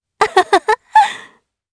Chrisha-Vox_Happy3_jp.wav